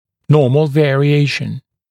[‘nɔːml ˌveərɪ’eɪʃn][‘но:мл ˌвэари’эйшн]вариация (-ии) в пределах нормы, варианты нормы